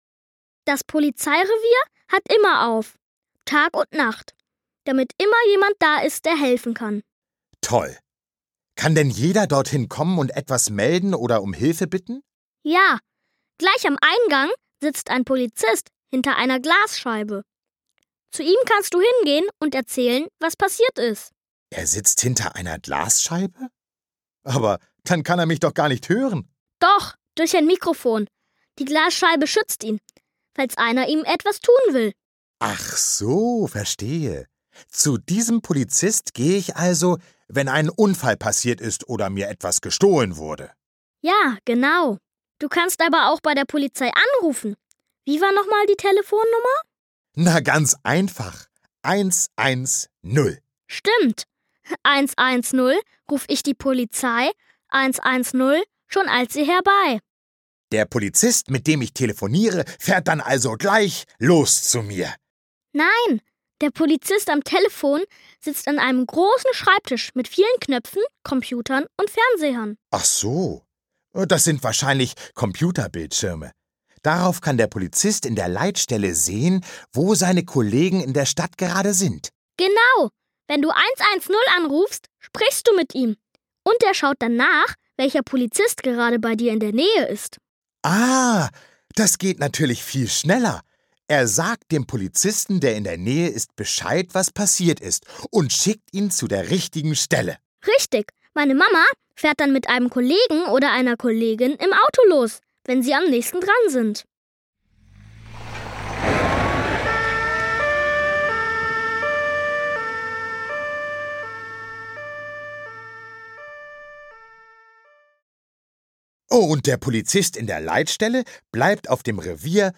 In diesem Hörspiel begleiten die Kleinen einen Schutzpolizistin und einen Hundeführer durch ihren Arbeitsalltag. Dabei lernen sie die Ausrüstung und die verschiedenen Aufgaben kennen, sind zu Besuch auf der Wache und erfahren, was Polizisten alles können und trainieren müssen. Und immer mit dabei: Harro, der Polizeihund, den die Kinder in einem lustigen Lied näher kennenlernen.